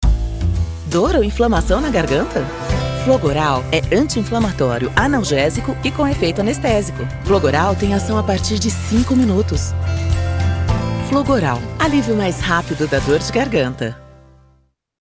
Trabalho em estúdio próprio totalmente equipado e entrego áudio de alta qualidade, com flexibilidade para atender necessidades exclusivas da sua marca, negócio ou projeto. Minha voz é versátil, polida e neutra, com interpretações personalizadas para seus objetivos.
Contralto